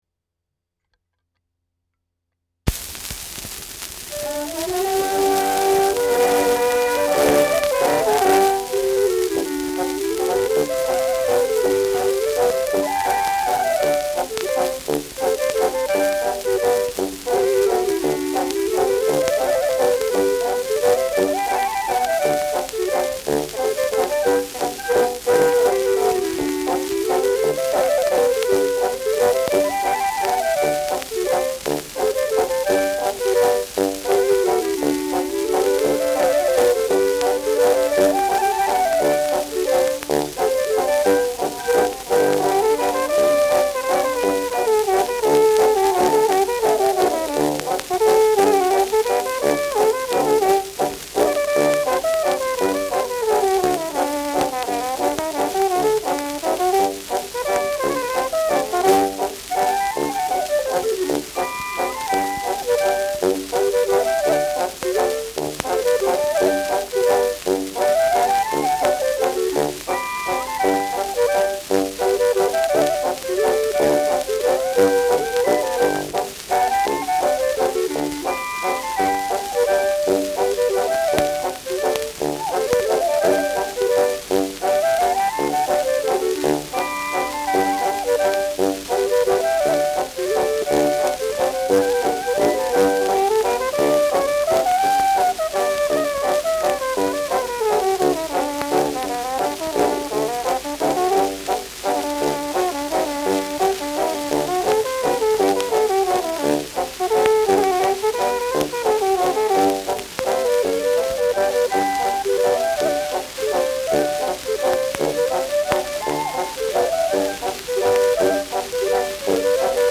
Schellackplatte
Tonrille: leichter Abrieb
präsentes Rauschen : Knistern
Dachauer Bauernkapelle (Interpretation)